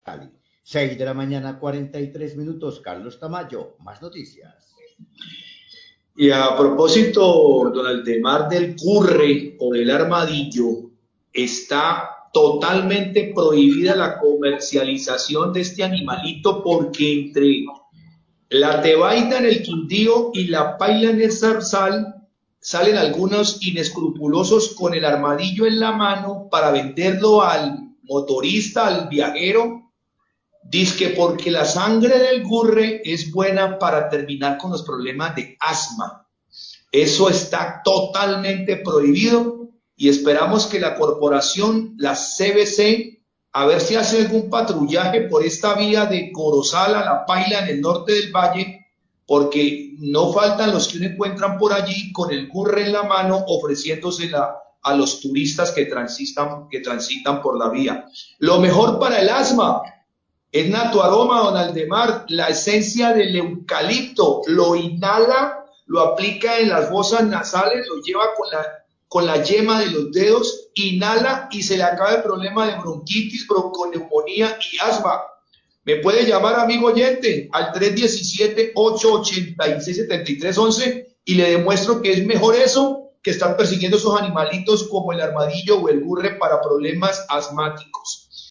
Radio
Periodista hace un llamado a la CVC para que haga vigilancia en la vía de Corozal a La Paila, donde personas venden armadillos como medicina para el asma. Recuerda que la caza y comercialización de este animal se encuentra prohibida.